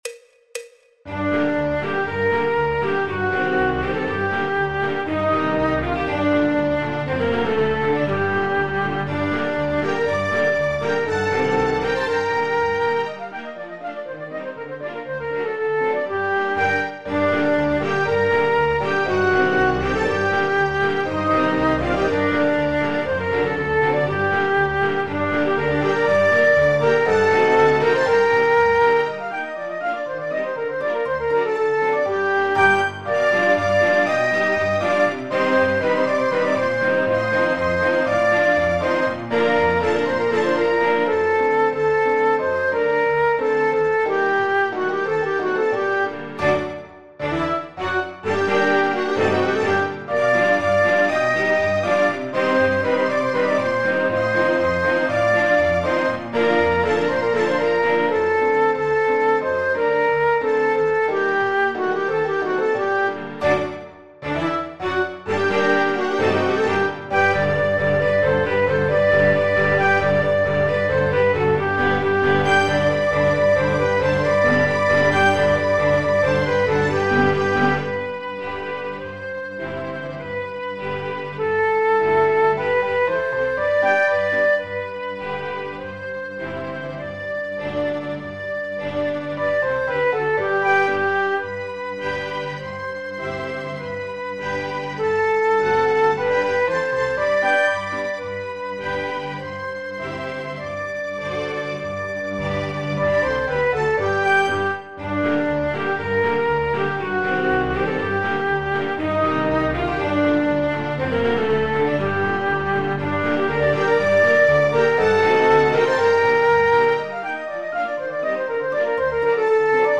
Popular/Tradicional